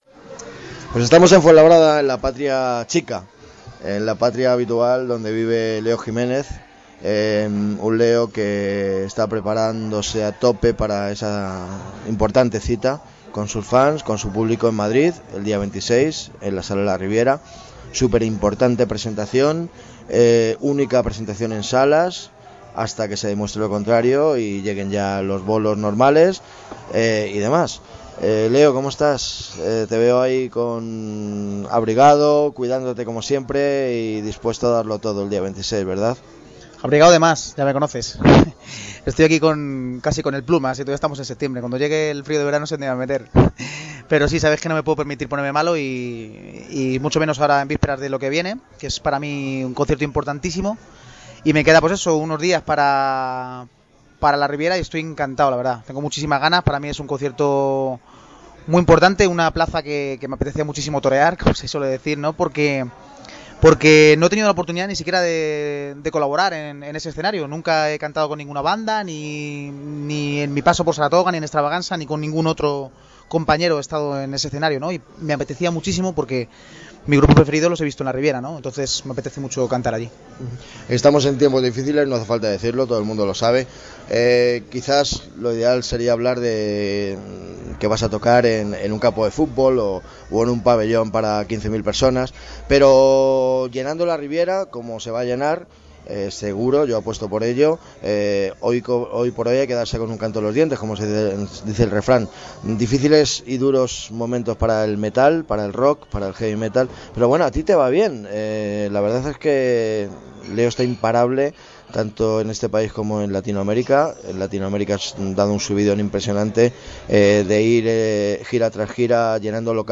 Entrevista a Leo Jiménez, sobre su concierto en La Riviera de Madrid y más cosas